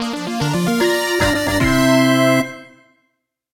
A song
Ripped from the game
applied fade out to last two seconds